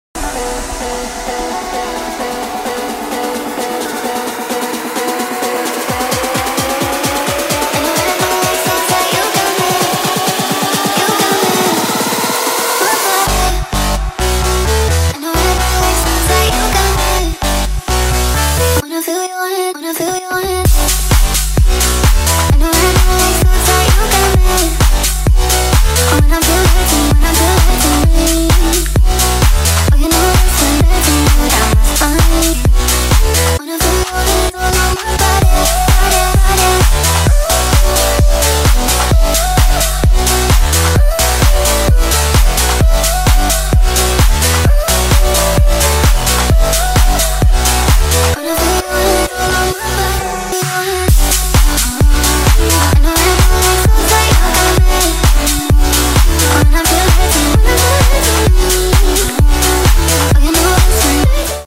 Cockpit view of flying the sound effects free download
Cockpit view of flying the extra330SC.